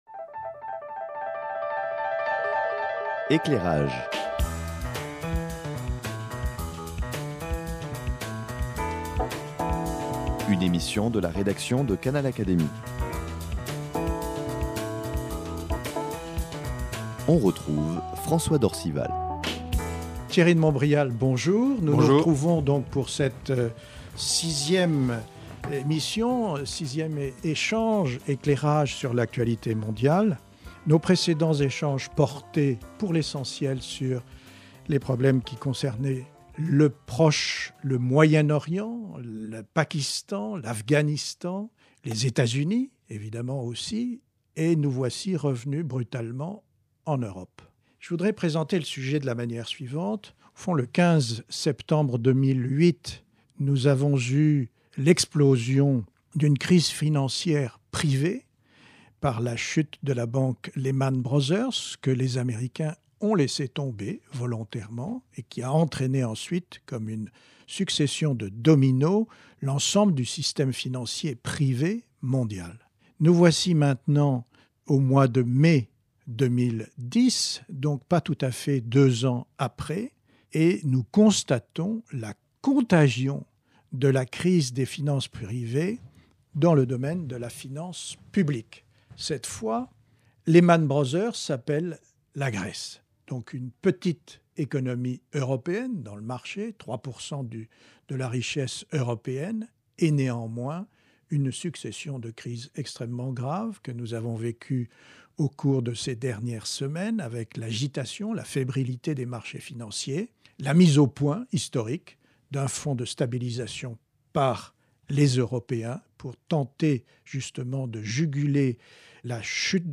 Enregistré durant la tempête financière provoquée par la crise grecque, ce sixième entretien entre Thierry de Montbrial et François d’Orcival, tous deux membres de l’Académie des sciences morales et politiques, est naturellement consacré à l’explication de ces événements.